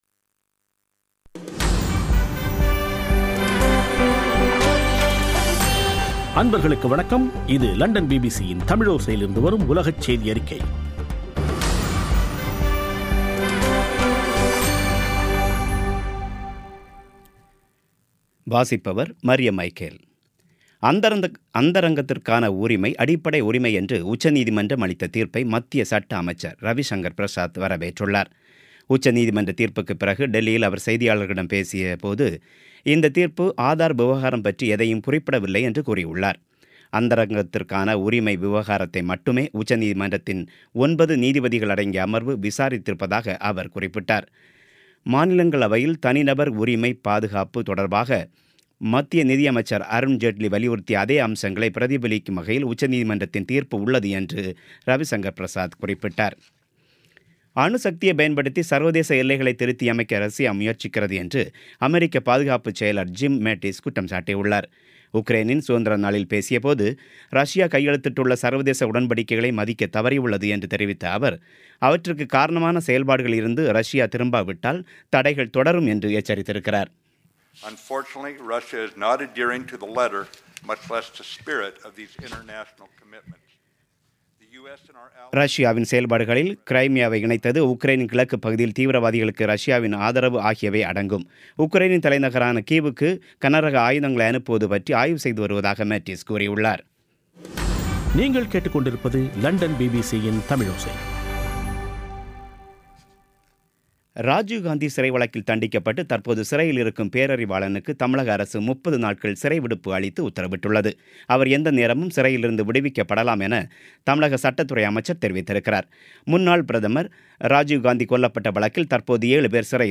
பிபிசி தமிழோசை செய்தியறிக்கை (24/08/2017)